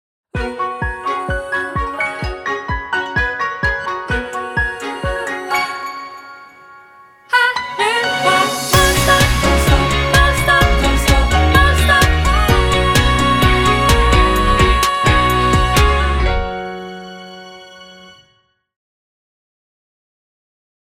SONG ROCK / POPS